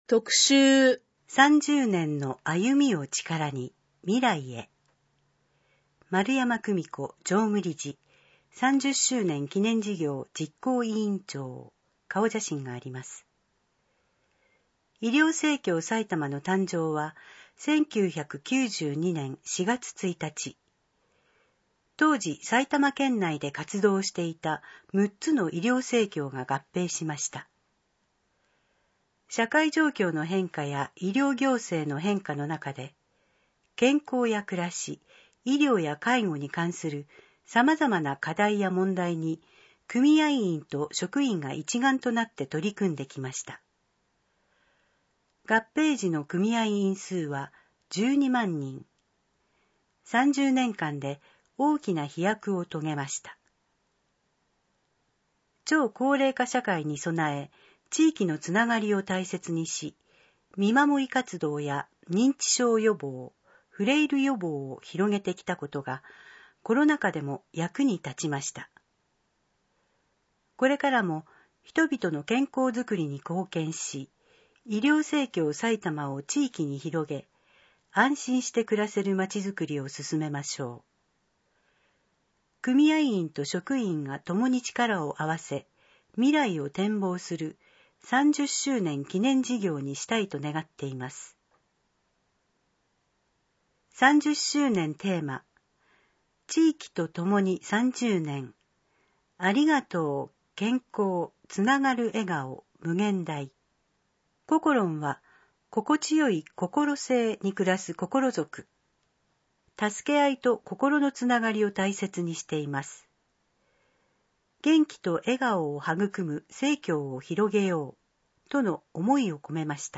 2022年7月号（デイジー録音版）